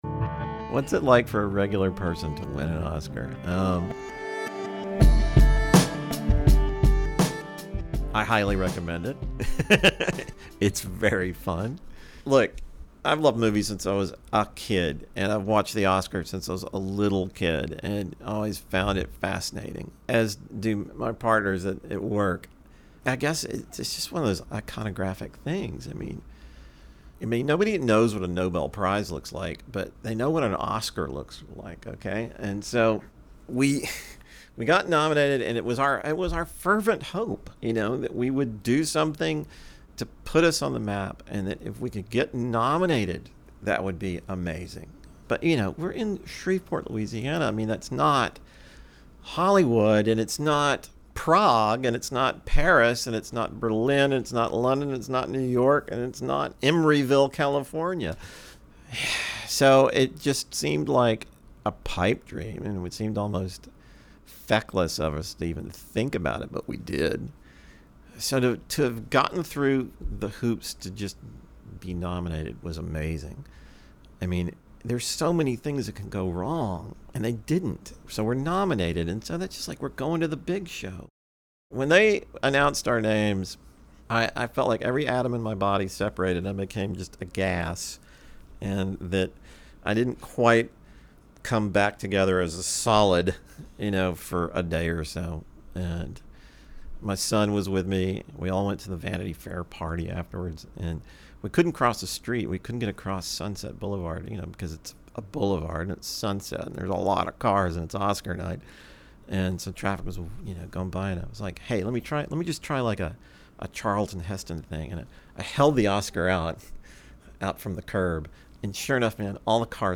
Transcript from Oscar Talk with William Joyce